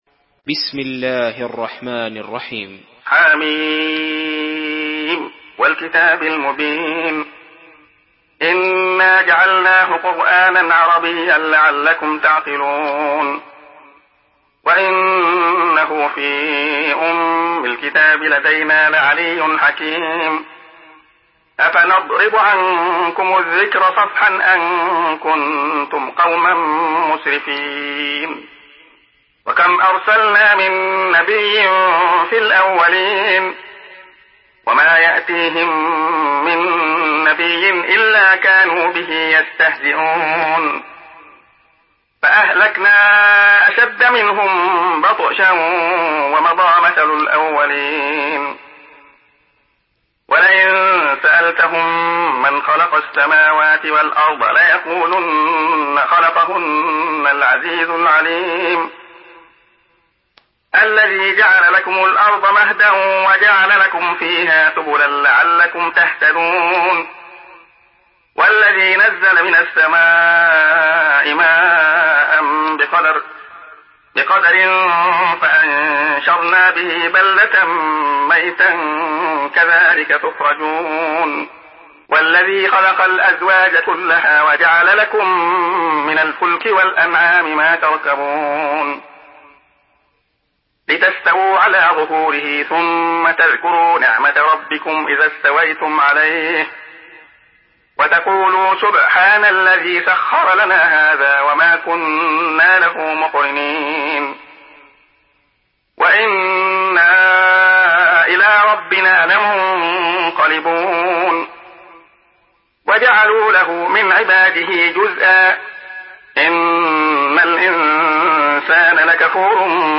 Surah আয-যুখরুফ MP3 in the Voice of Abdullah Khayyat in Hafs Narration
Murattal Hafs An Asim